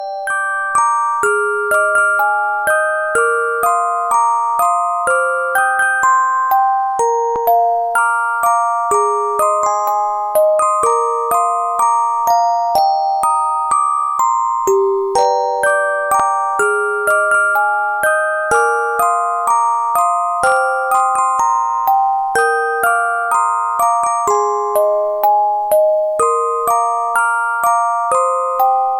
Rock bass , jrock